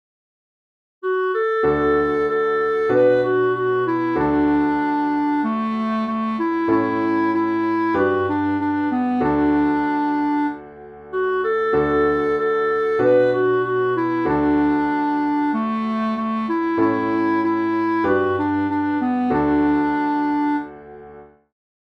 Waya Hey Hey Waya (Cançó tradicional cherokee)
Interpretació musical de la versió instrumental de la cançó tradicional cherokee